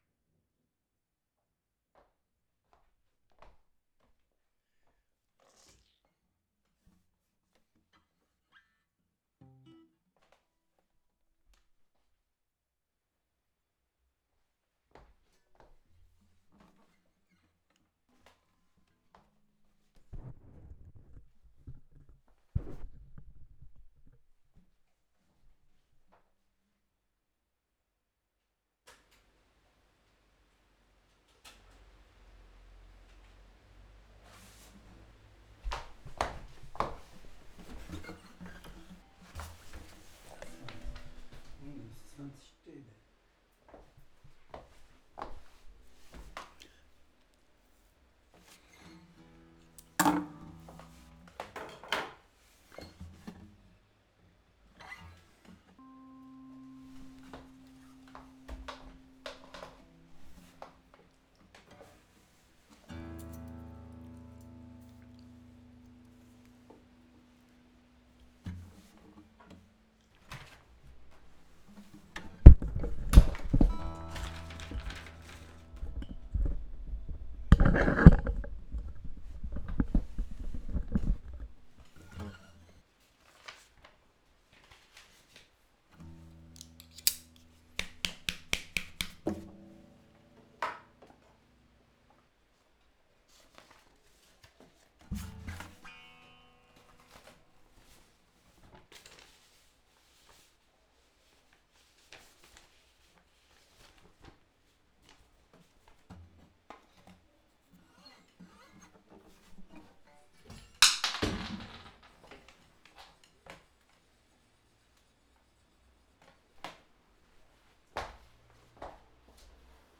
2015 Leerlaufcuts (Studio-Aufnahmen Leerstellen)